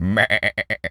pgs/Assets/Audio/Animal_Impersonations/goat_baa_calm_03.wav at master
goat_baa_calm_03.wav